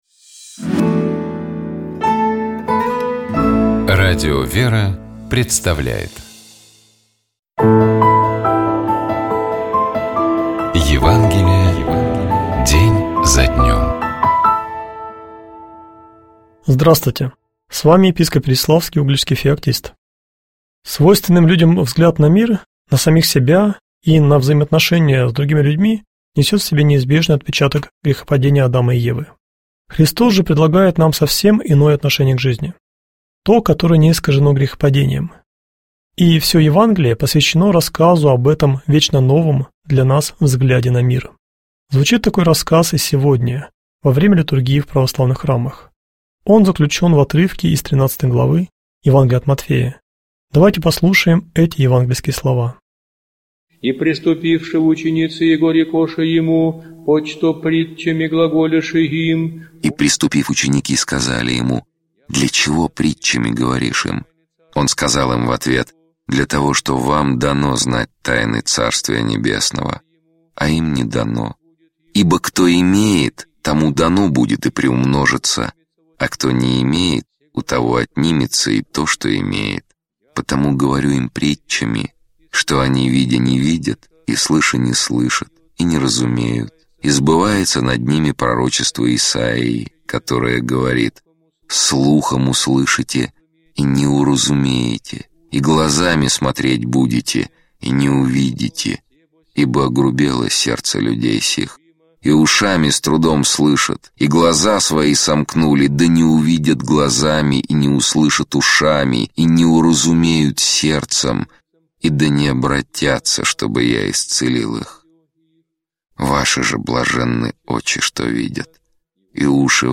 епископ Феоктист ИгумновЧитает и комментирует епископ Переславский и Угличский Феоктист